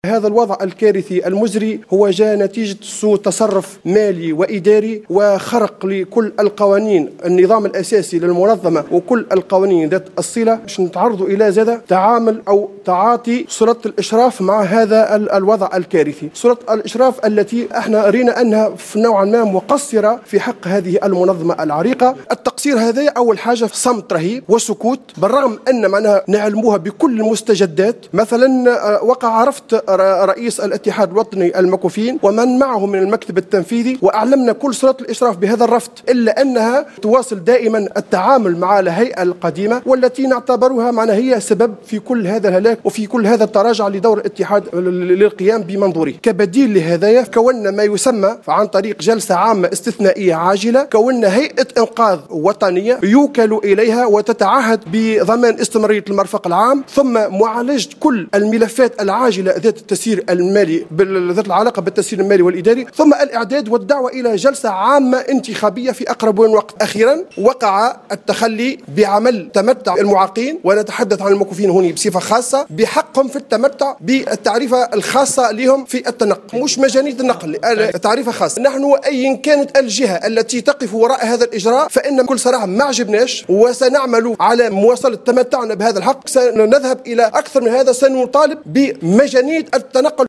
تصريح اعلامي